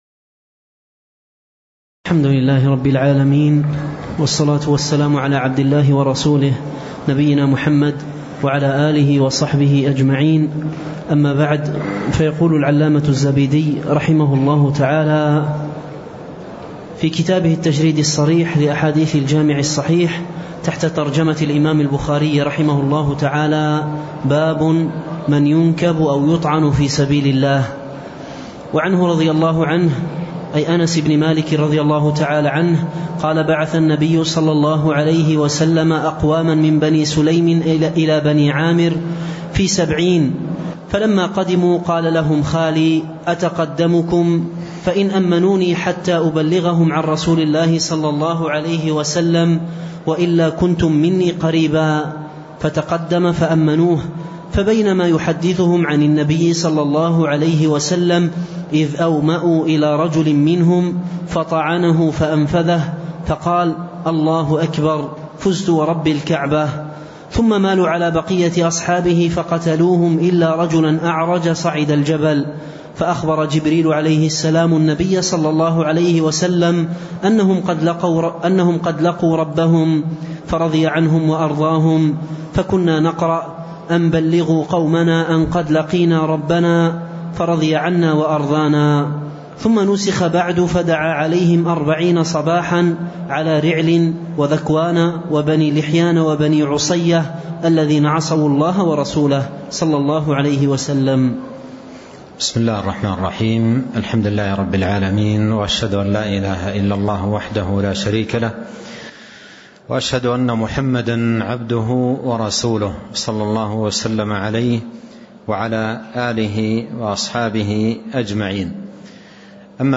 تاريخ النشر ١٤ صفر ١٤٣٥ هـ المكان: المسجد النبوي الشيخ